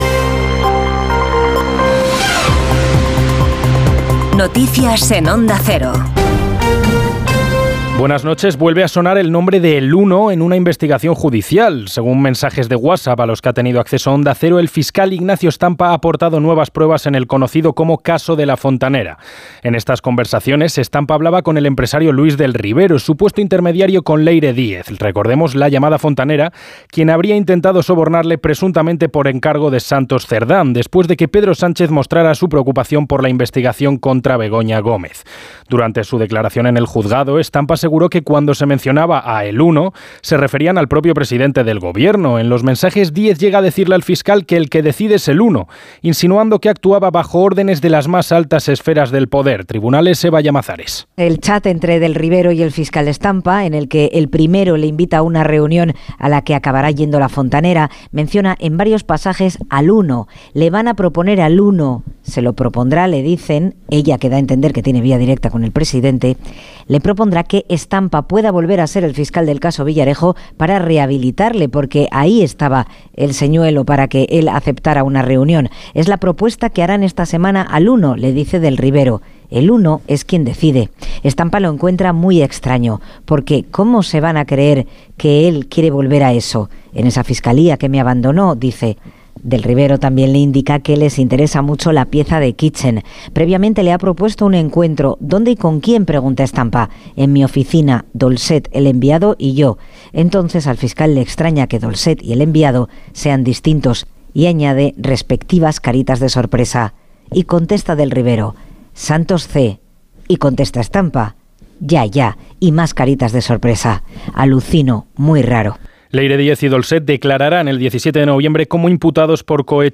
Las noticias en Onda Cero